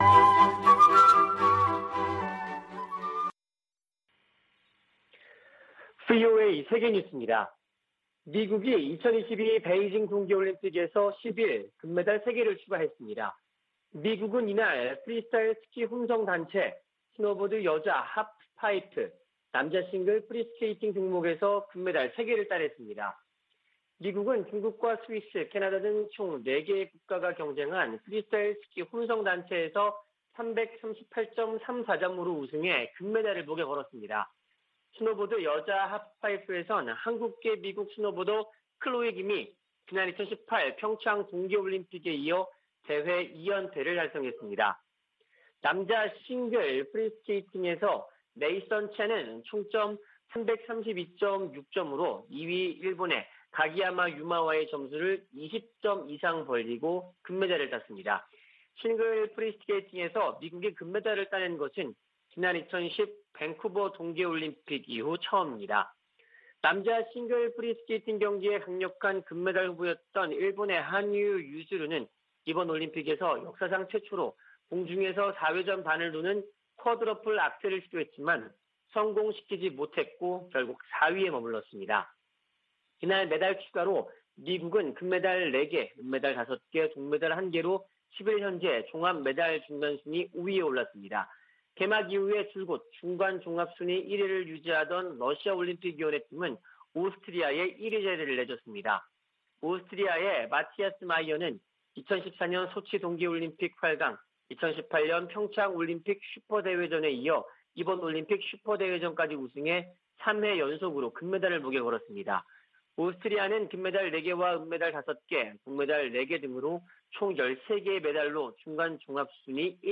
VOA 한국어 아침 뉴스 프로그램 '워싱턴 뉴스 광장' 2021년 2월 11일 방송입니다. 토니 블링컨 미 국무장관은 이번 주 미한일 외교장관 회동이 북한의 도전 등 의제를 전진시킬 중요한 순간이라고 밝혔습니다.